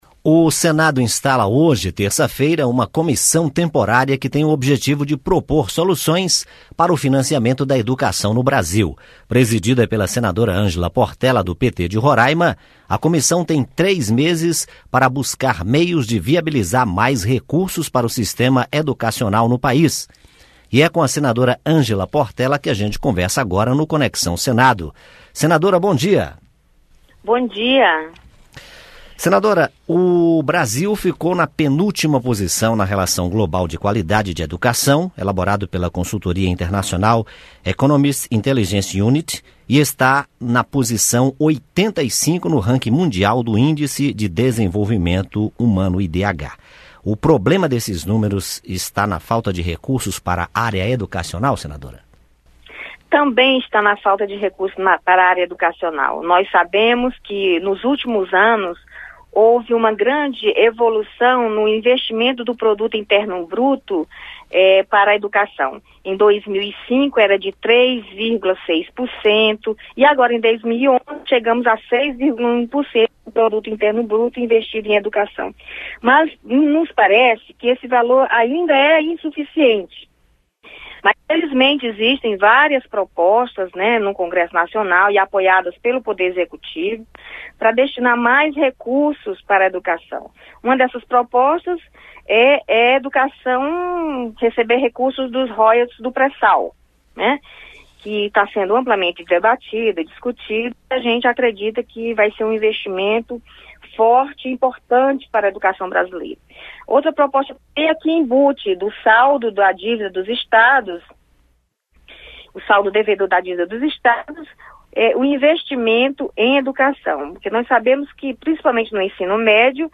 Entrevista com a senadora Angela Portela (PT-RR).